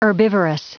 Prononciation du mot herbivorous en anglais (fichier audio)
Prononciation du mot : herbivorous